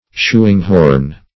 Shoehorn \Shoe"horn`\, Shoeing-horn \Shoe"ing-horn`\, n.